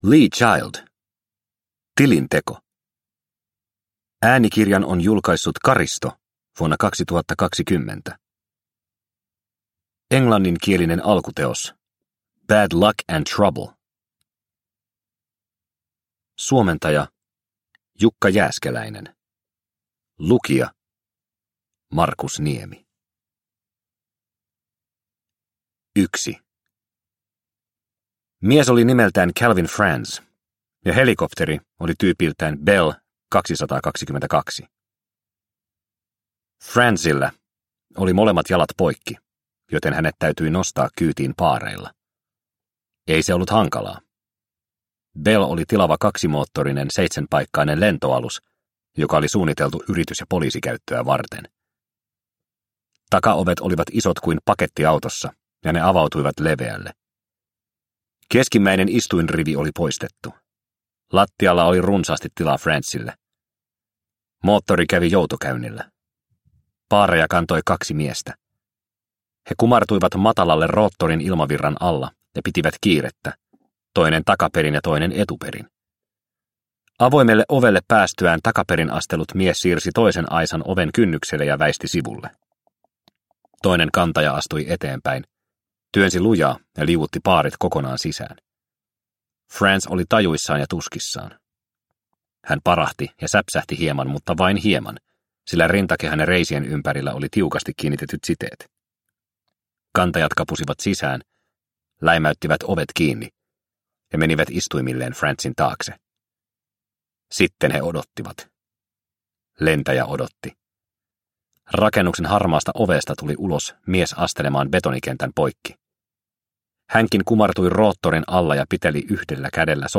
Tilinteko – Ljudbok – Laddas ner